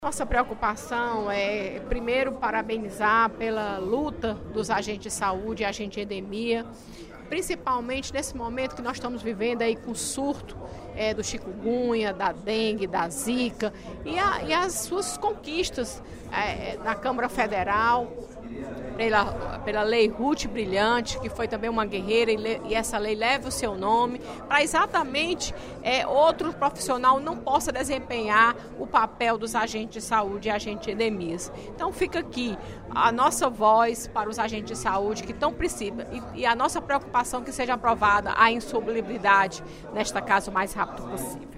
A deputada Fernanda Pessoa (PR) ressaltou a aprovação da chamada Lei Ruth Brilhante, pela Câmara Federal, assegurando que o trabalho dos agentes comunitários de saúde e endemias é exclusivo desses profissionais. O pronunciamento foi feito durante o primeiro expediente da sessão plenária desta quarta-feira (07/06).